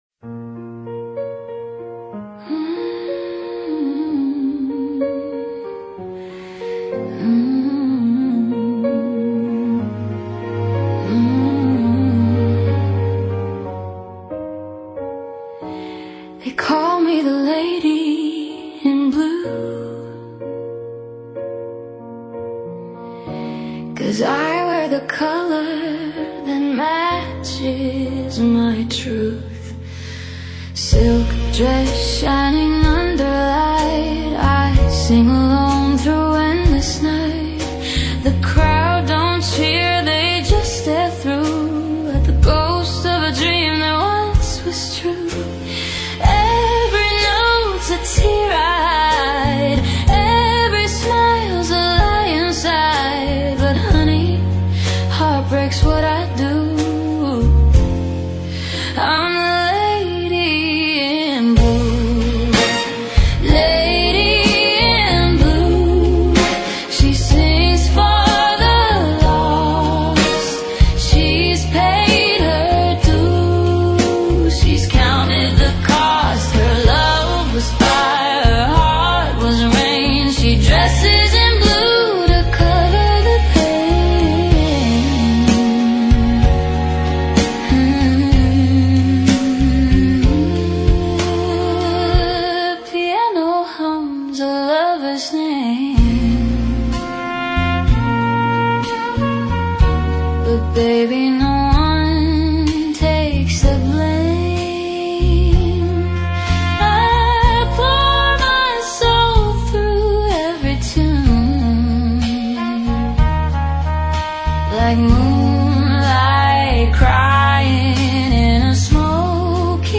Torch Jazz Ballad